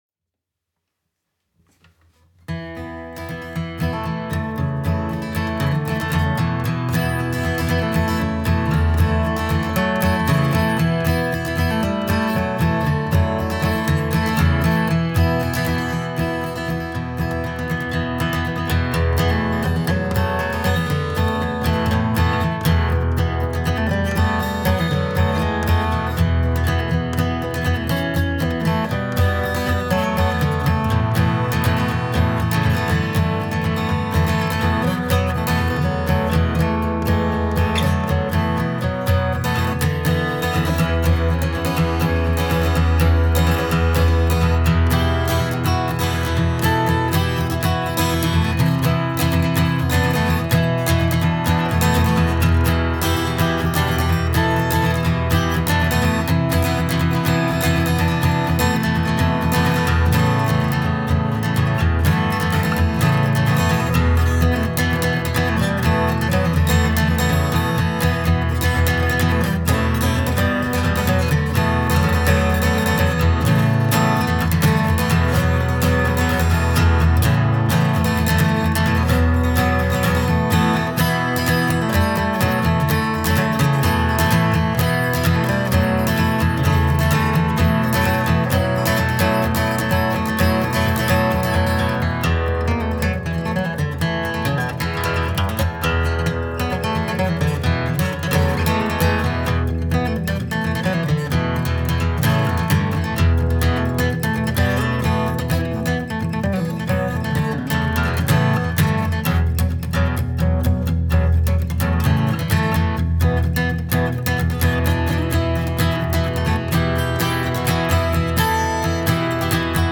Bändchenmikrofon / Achter Charakteristik Aufnahme Tipps
Anhang anzeigen 120455 Bei den Tiefen würde ich noch etwas wegnehmen, aber so im Solokontext hat das was "wuchtiges" Zum Vergrößern anklicken....